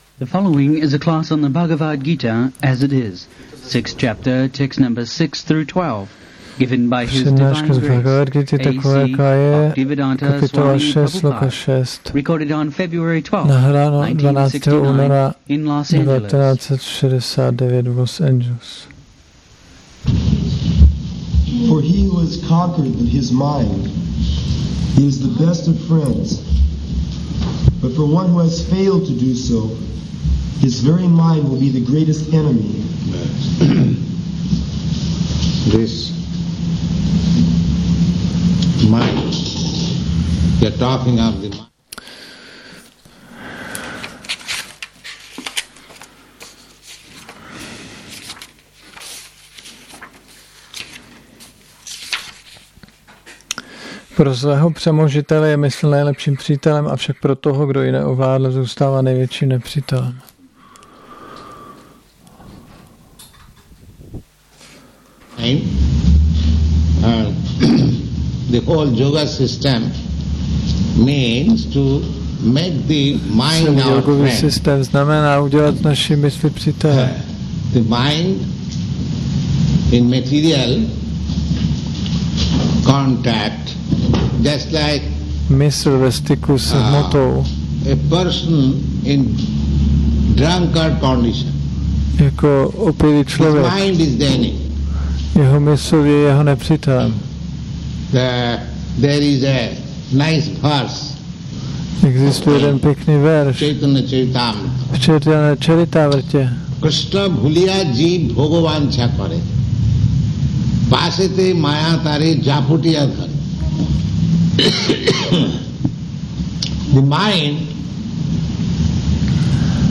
1969-02-12-ACPP Šríla Prabhupáda – Přednáška BG-6.6-12 Los Angeles